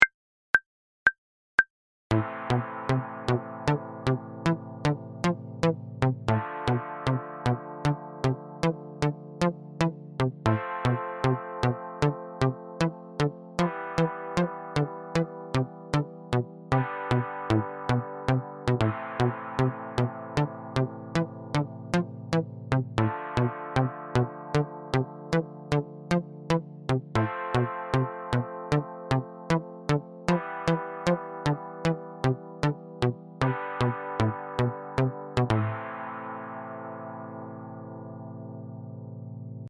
Dotted 8th note groove displacement PDF and mp3s.